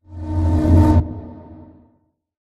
Sound / Minecraft / mob / zombie / unfect / wood.ogg
wood.ogg